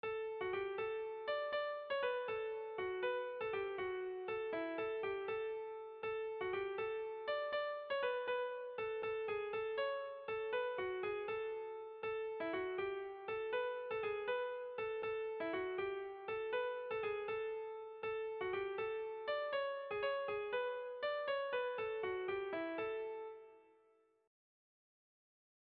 Zortziko handia (hg) / Lau puntuko handia (ip)
A1A2BD